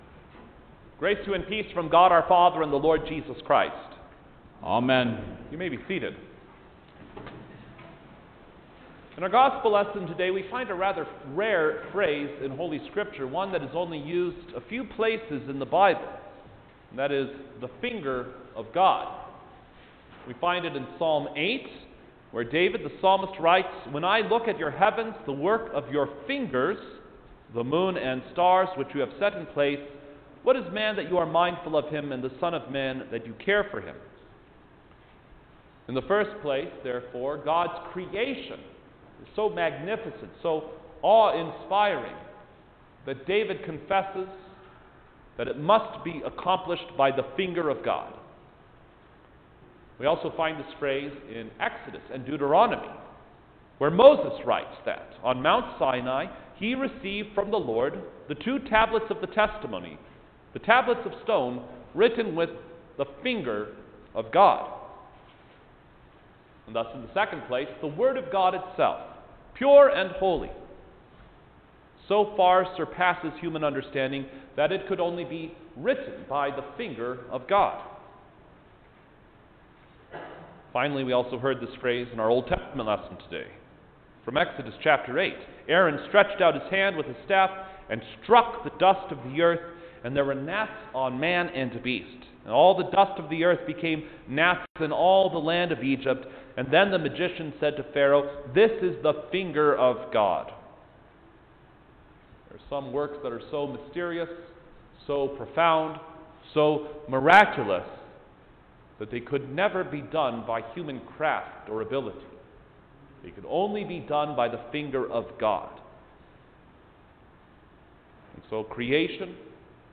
March-7_2021-Third-Sunday-in-Lent_Oculi_Sermon_Stereo.mp3